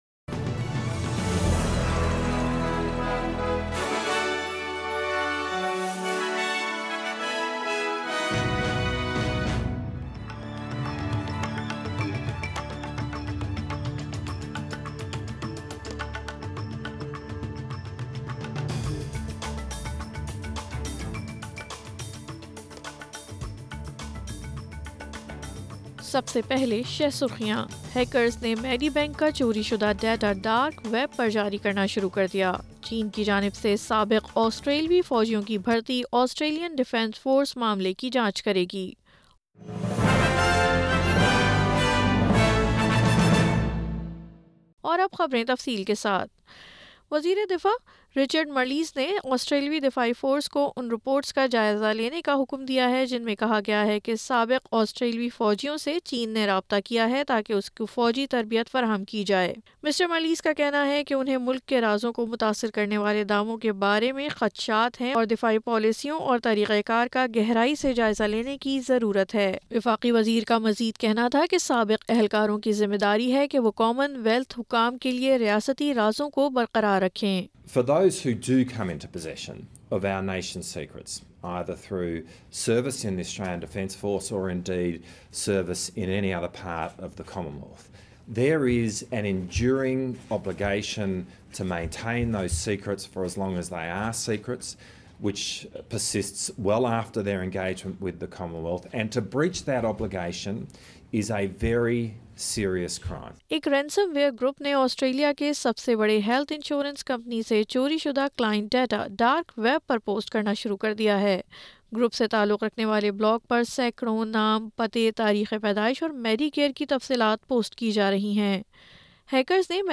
Urdu News Wednesday 09 November 2022